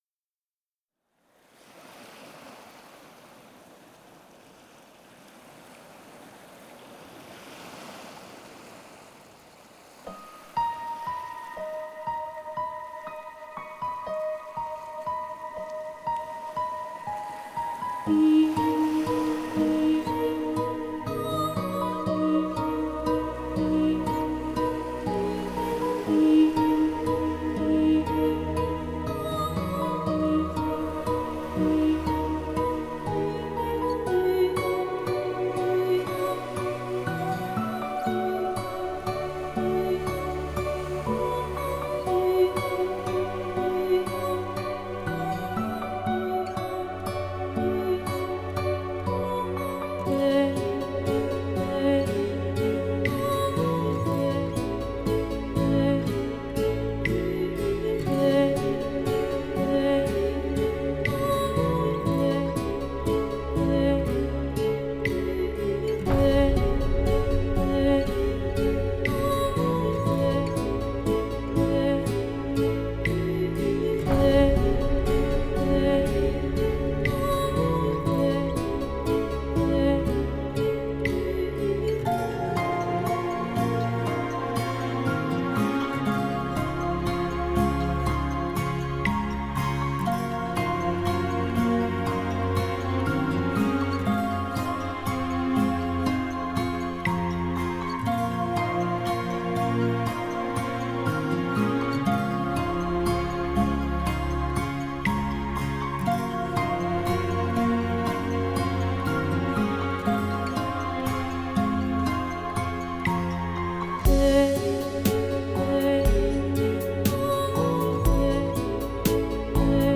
Музыка релакс Relax Музыка нью эйдж New age Нью эйдж